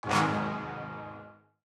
TM-88 Hits [Goonies]_2.wav